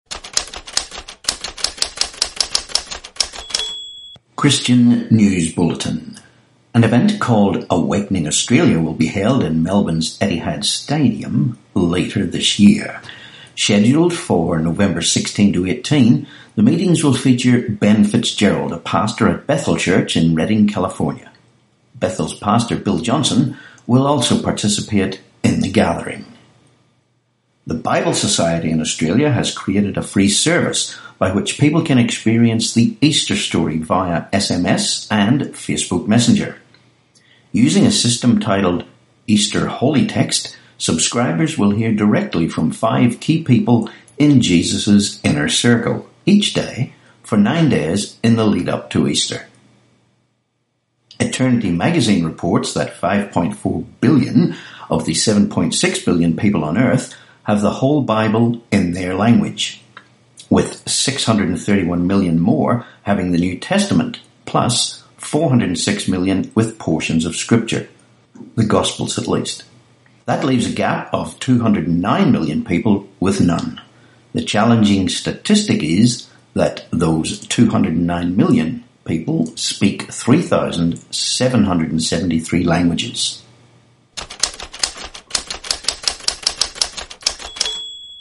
25Mar18 Christian News Bulletin